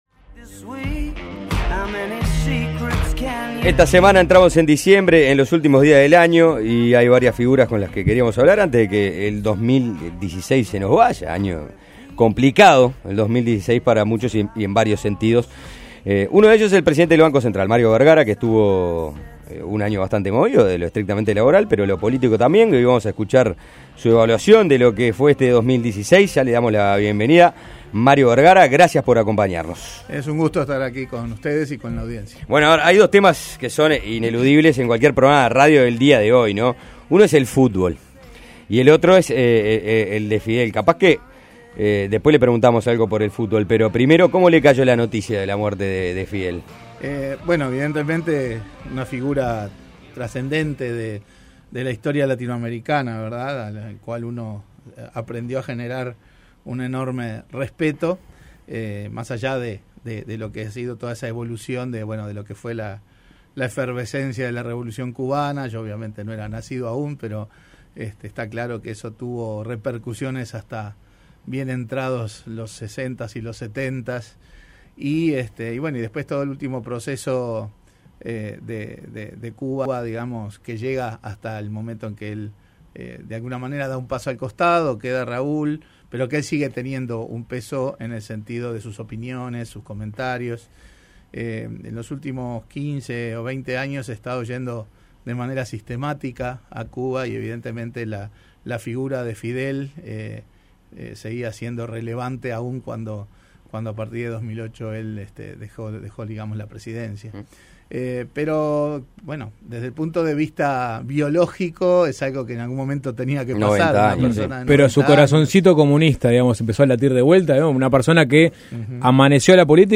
Entrevista en Suena Tremendo